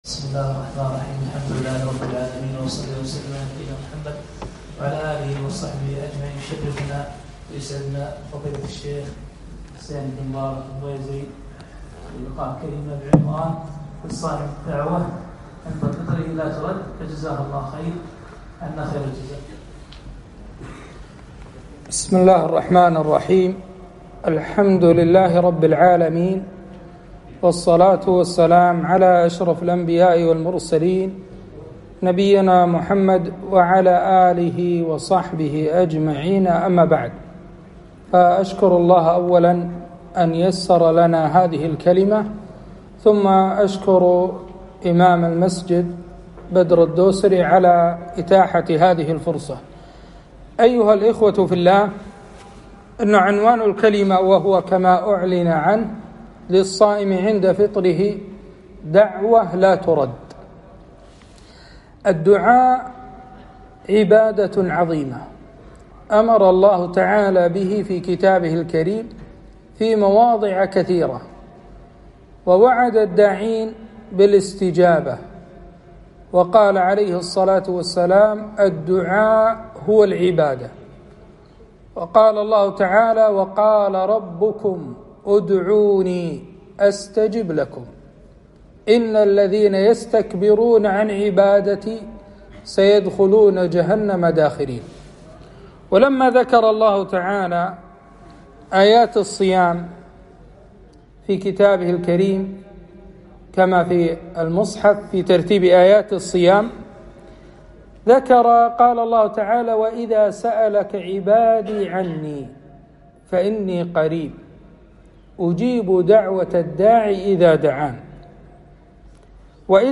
كلمة - للصائم عند فطره دعوة لاترد